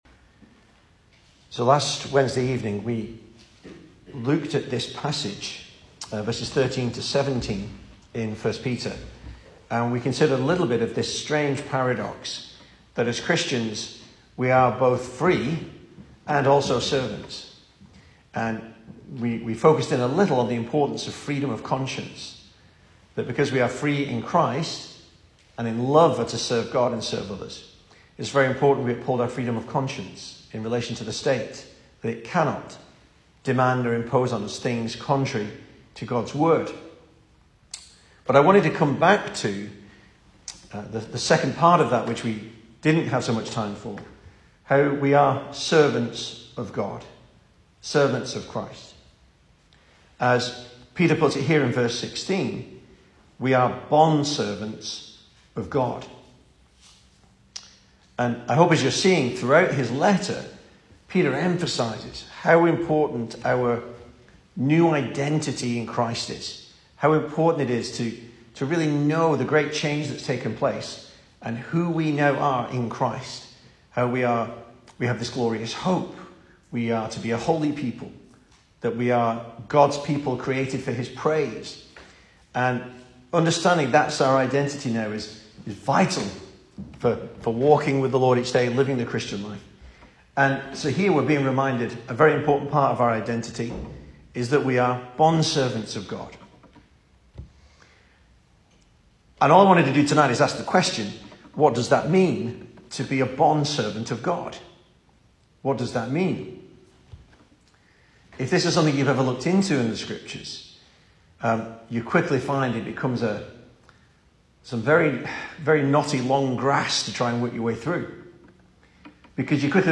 2023 Service Type: Weekday Evening Speaker